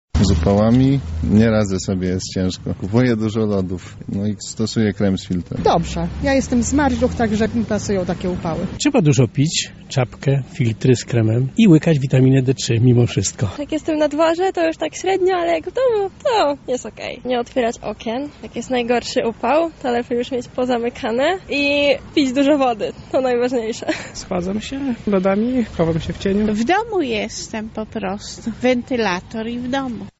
Zapytaliśmy więc mieszkańców Lublina o to, jak oni radzą sobie z wysokimi temperaturami.
SONDA